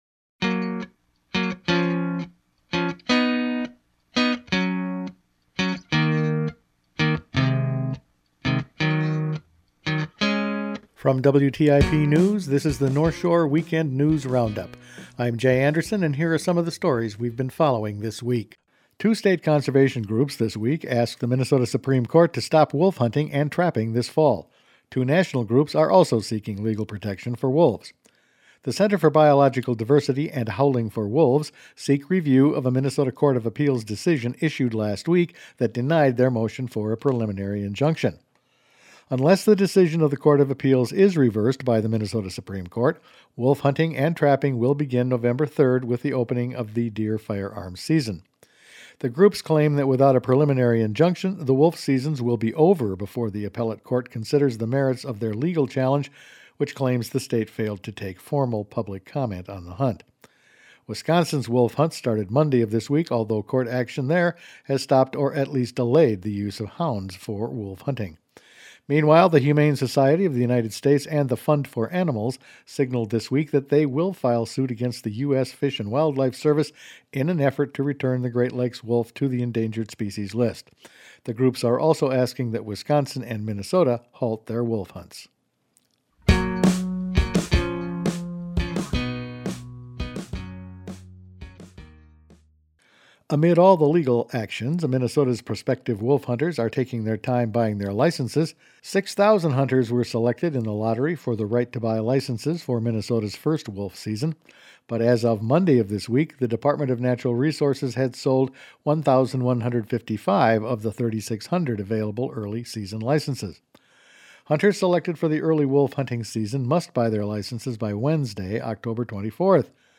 Each weekend WTIP news produces a round up of the news stories they’ve been following this week. The wolf season will go forward, drownings on Alton Lake, hot 8th Congressional District contest and steel strike averted…all in this week’s news.